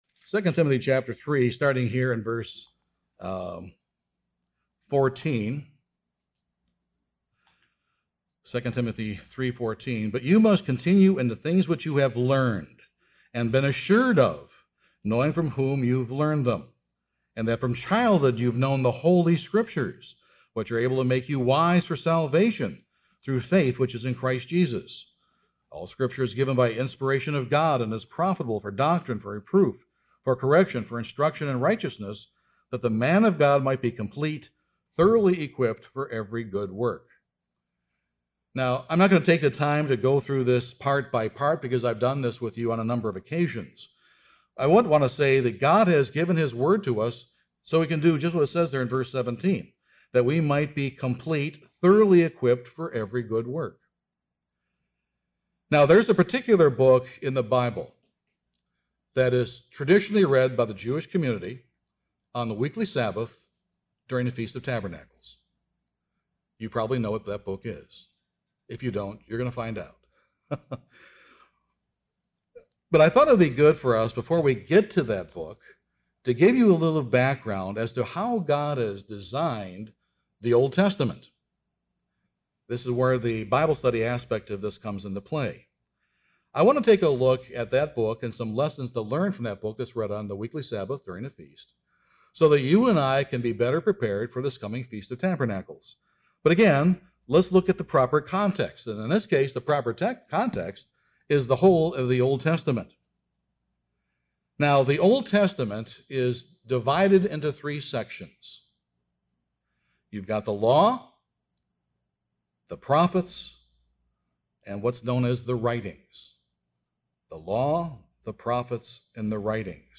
This sermon briefly summarizes how God has put together the Old Testament, and in particular, the Book of Ecclesiastes. Each year this Book is read by the Jewish community to reinforce in their minds how meaningless life is apart from God.